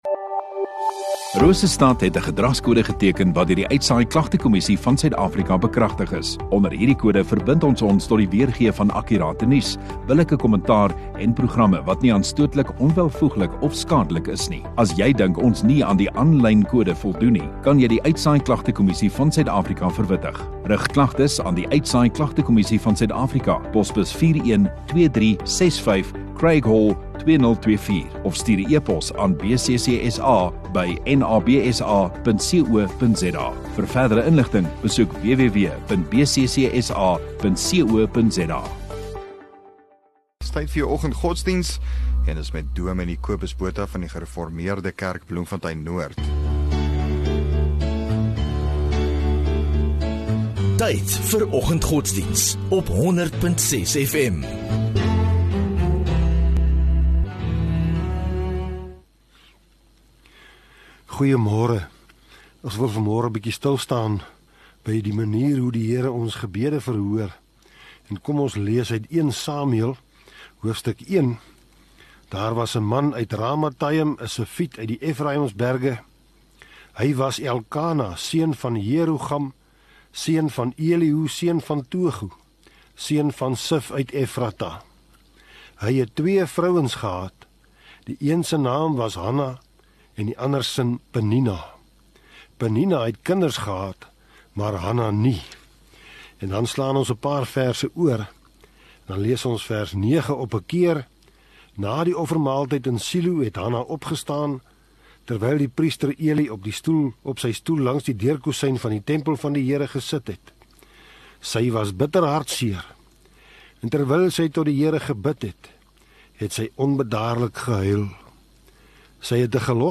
3 Mar Maandag Oggenddiens